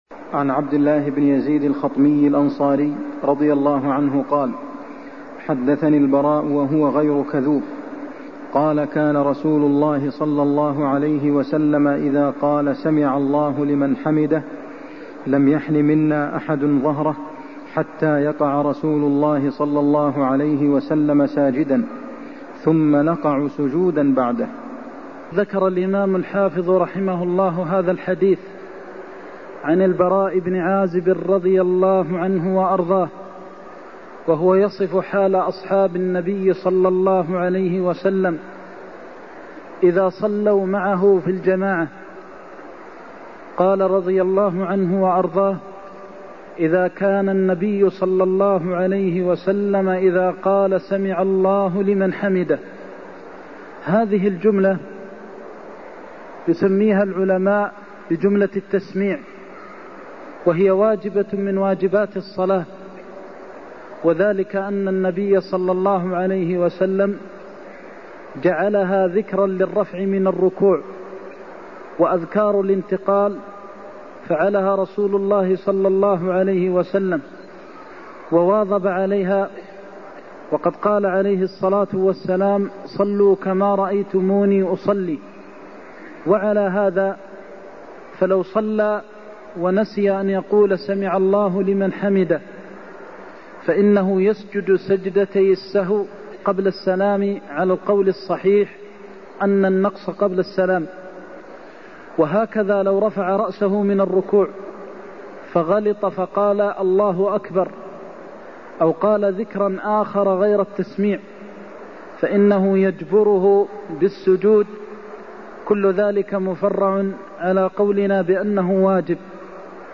المكان: المسجد النبوي الشيخ: فضيلة الشيخ د. محمد بن محمد المختار فضيلة الشيخ د. محمد بن محمد المختار سمع الله لمن حمده في الرفع من الركوع (74)